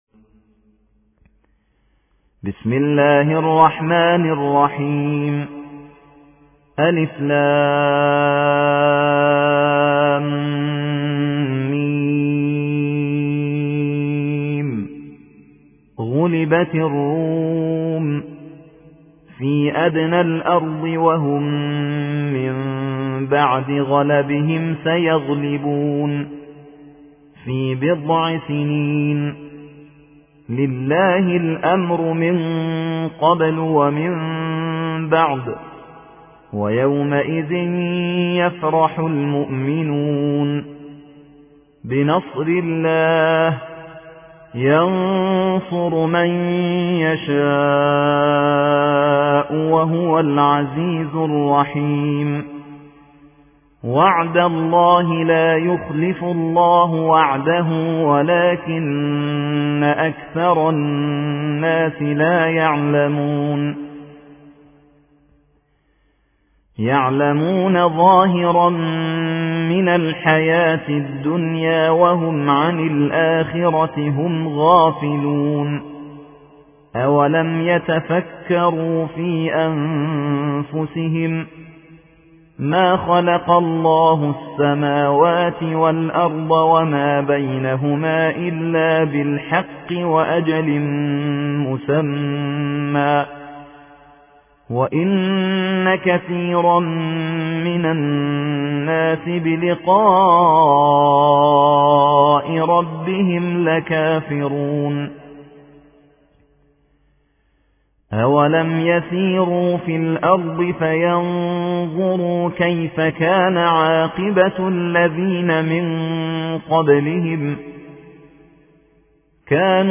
30. سورة الروم / القارئ